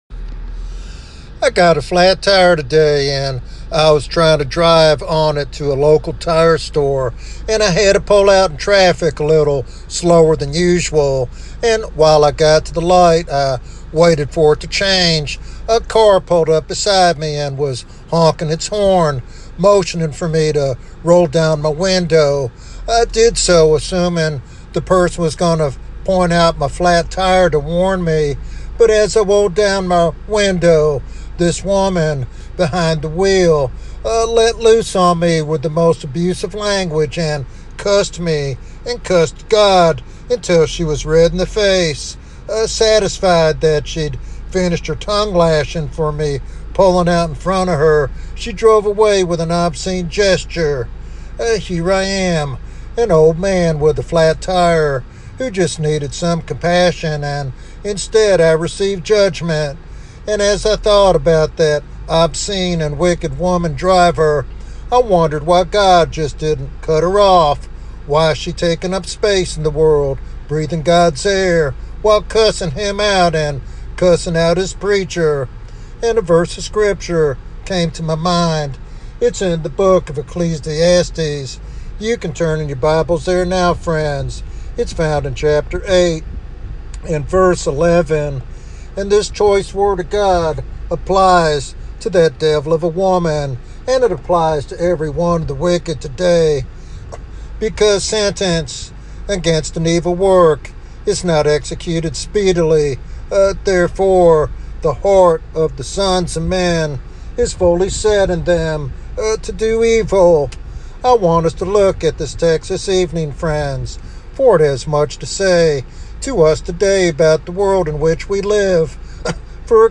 This sermon challenges listeners to consider their spiritual witness and readiness before the coming judgment.